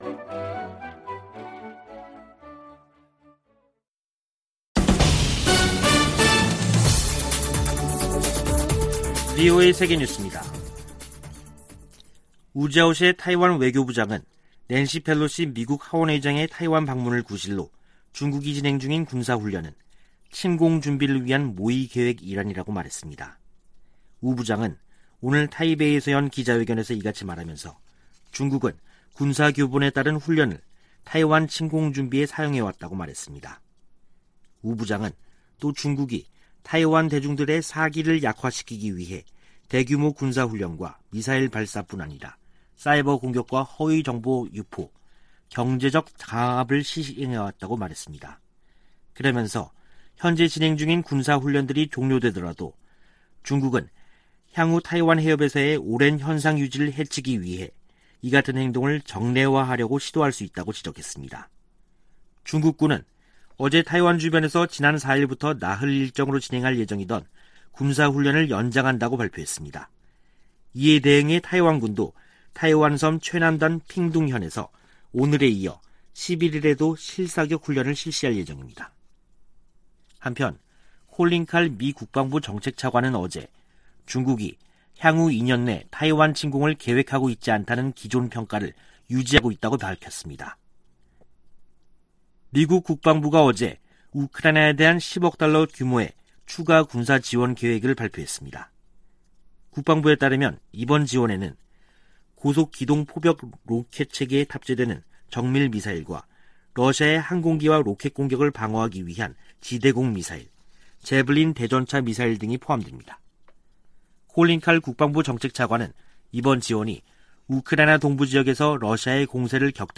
VOA 한국어 간판 뉴스 프로그램 '뉴스 투데이', 2022년 8월 9일 3부 방송입니다. 미국 정부가 북한 해킹조직 라자루스가 탈취한 가상화폐의 세탁을 도운 믹서 업체를 제재했습니다. 국무부는 우크라이나 친러시아 세력의 독립인정을 강력히 규탄하며 북한 노동자 파견은 대북 제재 위반이라는 점을 분명히 했습니다. 미 하원의원들이 베트남전쟁에 미군과 함께 참전했던 미국 내 한인들에게 의료 혜택을 제공하는 입법을 촉구했습니다.